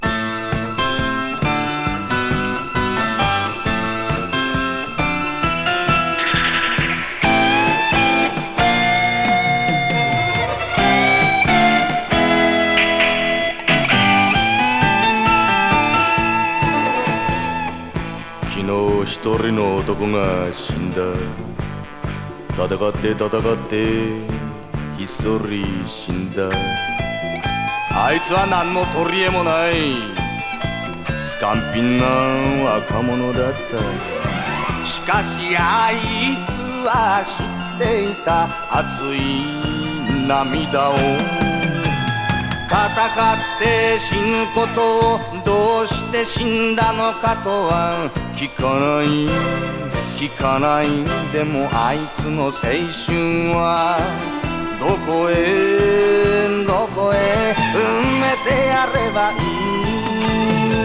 ズンドコ・ディスコ・グルーヴ！